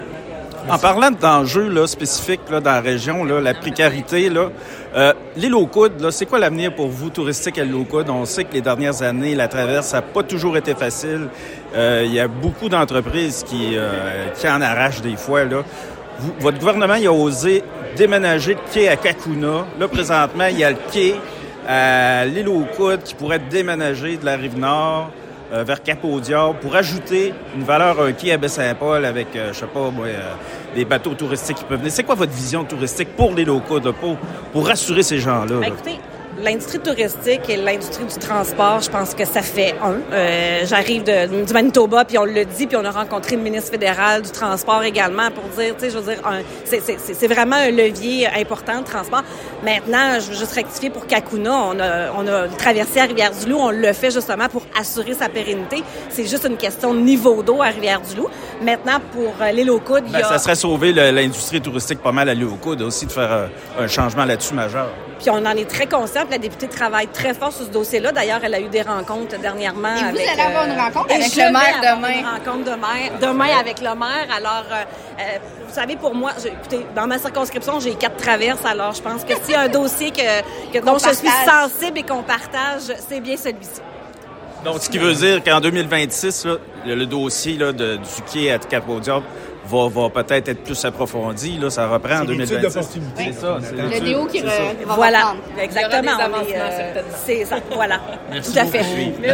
En marge de cet événement Média Photos Charlevoix a été en mesure de poser une question, lors d’un point de presse impromptu, à la ministre actuelle du Tourisme au Québec, Amélie Dionne concernant l’avenir touristique de l’Isle-aux-Coudres, précisément.